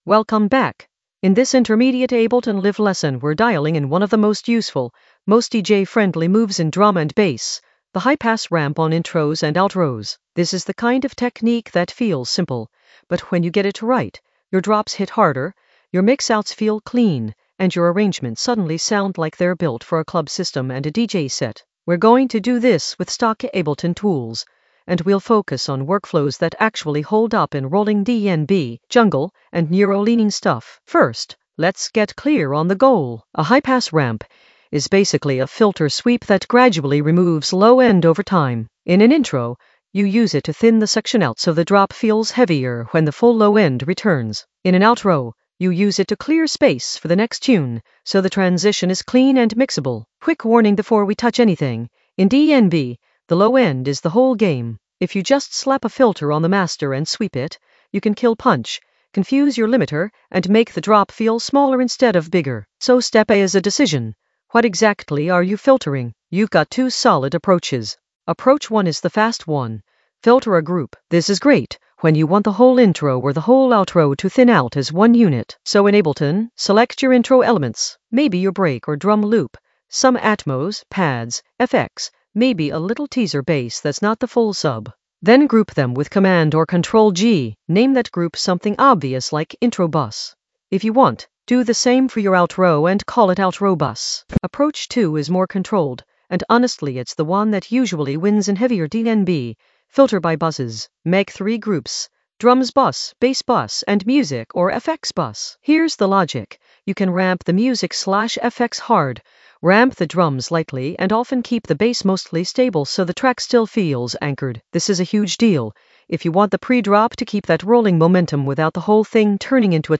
Narrated lesson audio
The voice track includes the tutorial plus extra teacher commentary.
An AI-generated intermediate Ableton lesson focused on High pass ramps on intros and outros in the Automation area of drum and bass production.